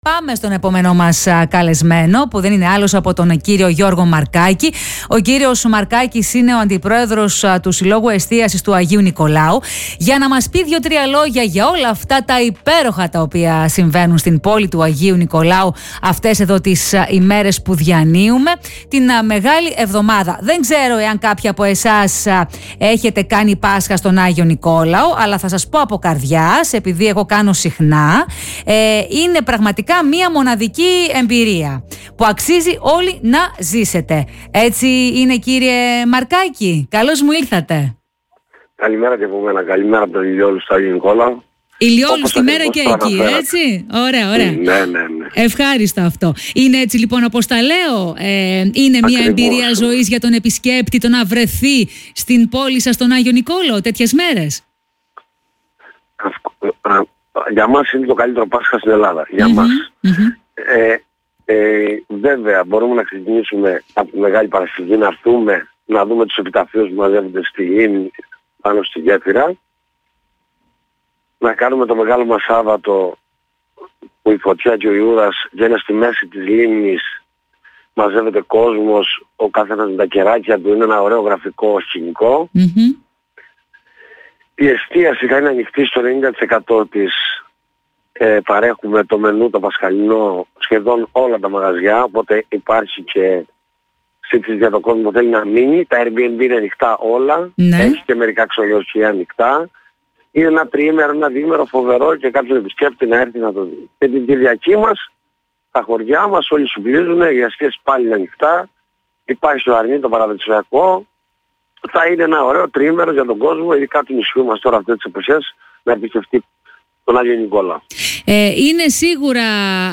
Σήμερα στο ραδιόφωνο του Politica 89.8